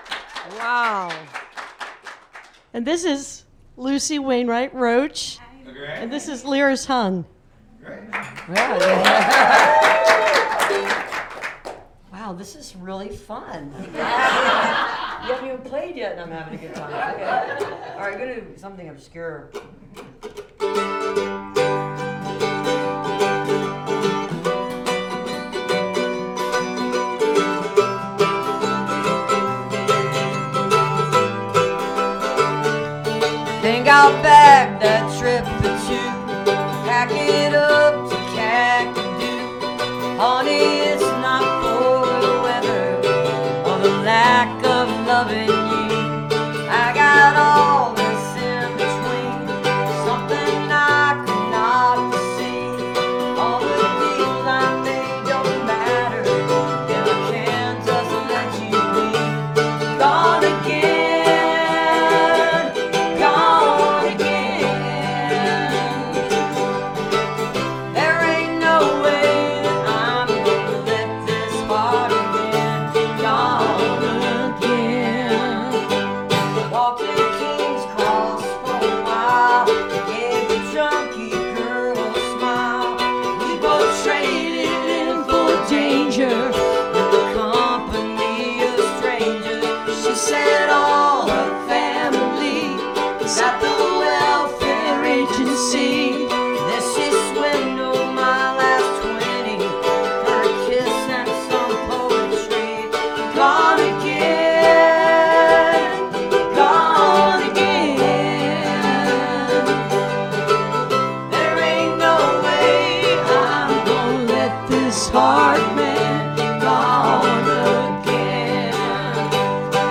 (captured from a youtube video)